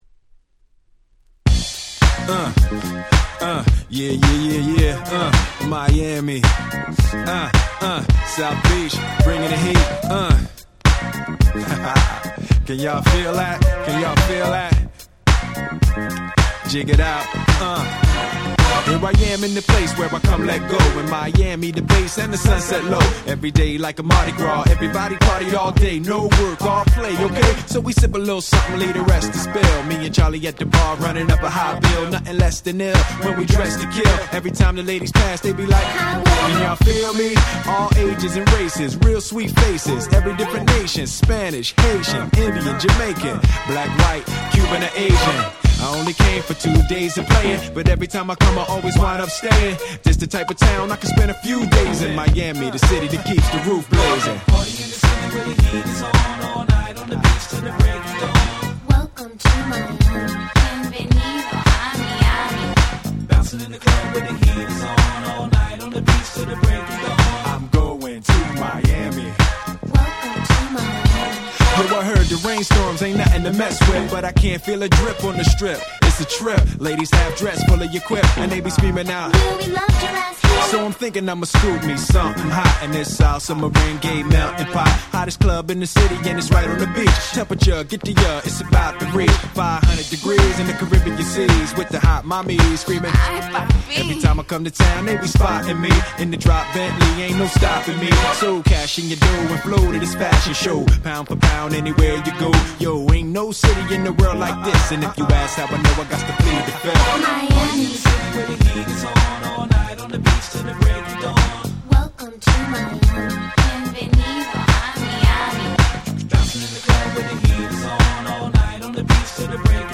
98' Super Hit Hip Hop !!